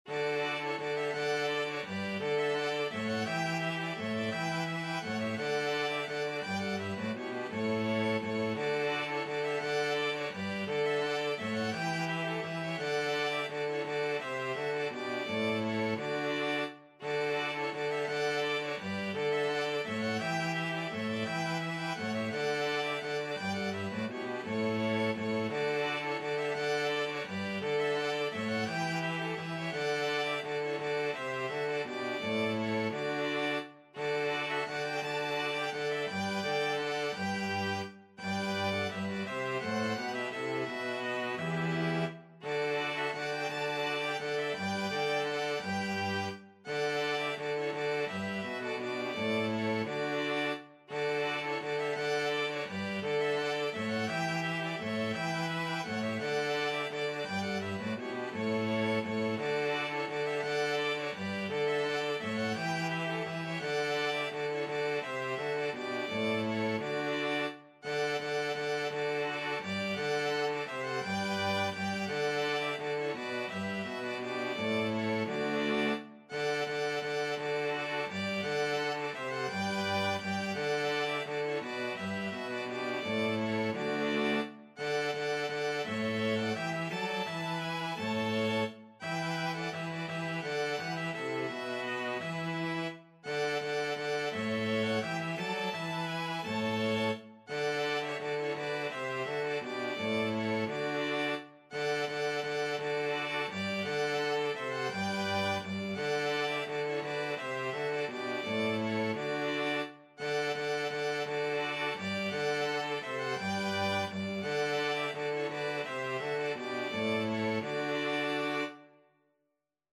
Free Sheet music for String Quartet
Violin 1Violin 2ViolaCello
D major (Sounding Pitch) (View more D major Music for String Quartet )
3/2 (View more 3/2 Music)
Classical (View more Classical String Quartet Music)
danserye_2_bergerette_STRQ.mp3